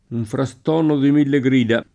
frastuono [ fra S t U0 no ] s. m.